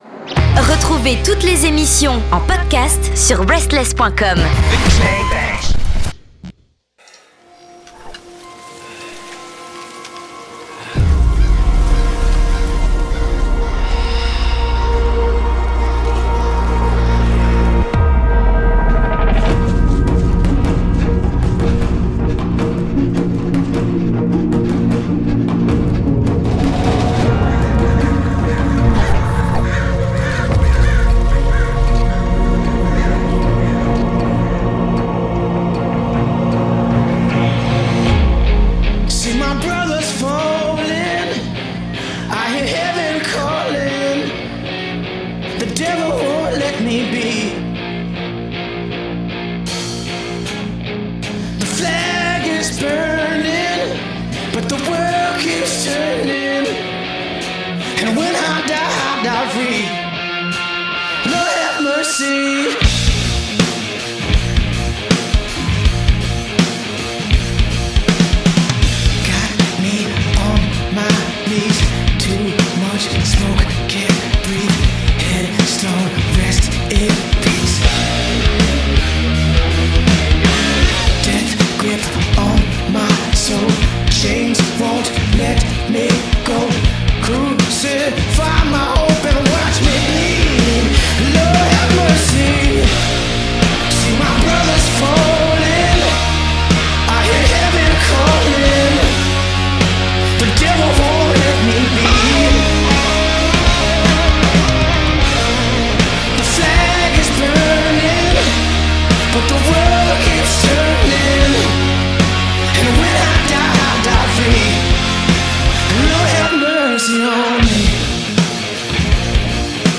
Interview AYRON JONES